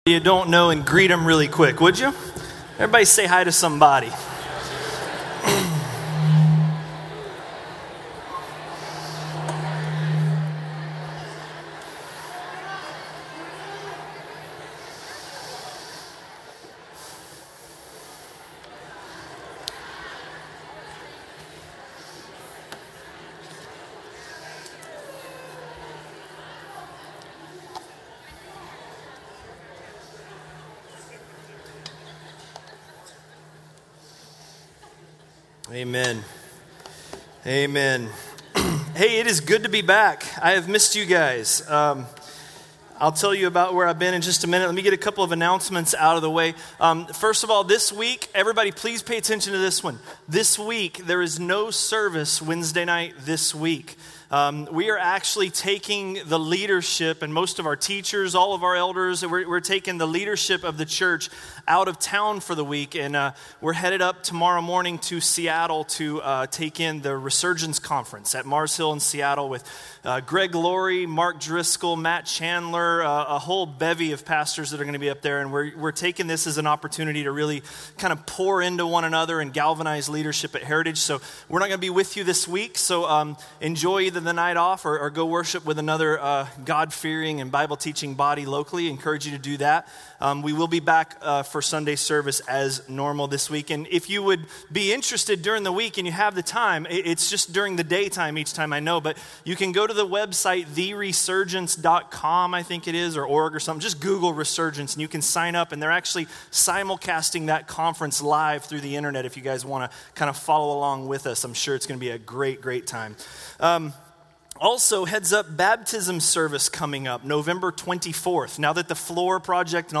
A message from the series "1 Corinthians." 1 Corinthians 9:19–9:23